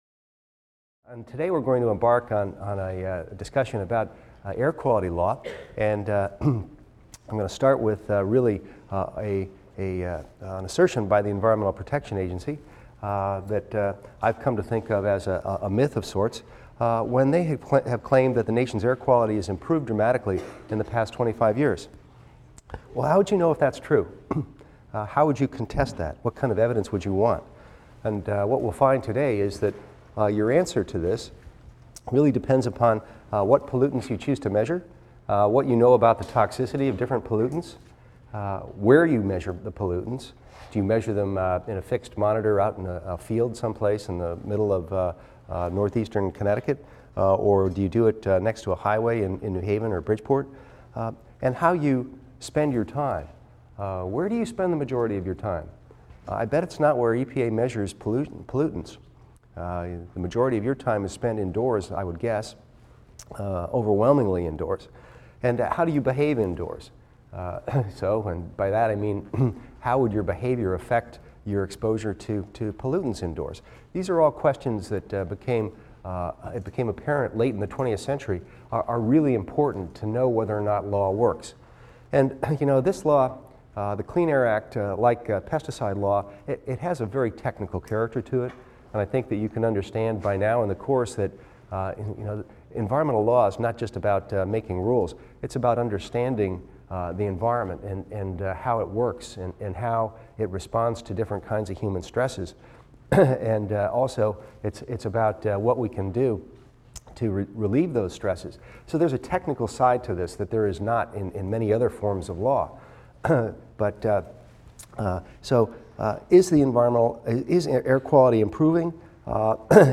EVST 255 - Lecture 12 - Air Quality Law: Margins of Safety | Open Yale Courses